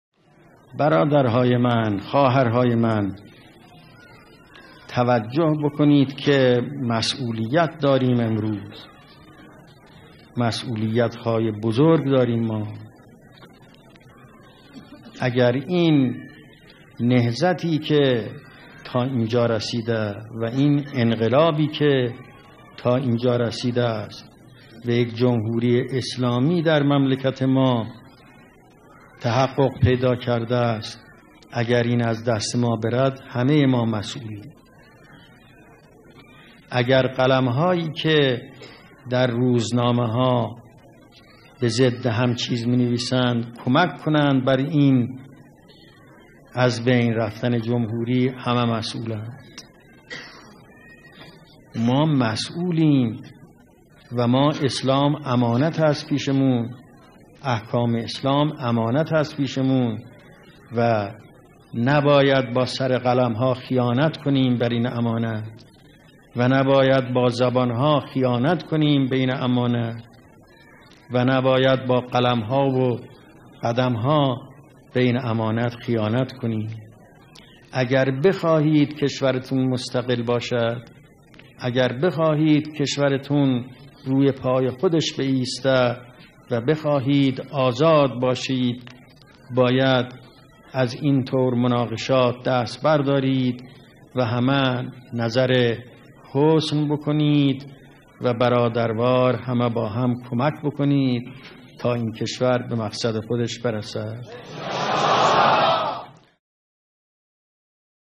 فرازی از بیانات امام خمینی (رضوان الله تعالی علیه)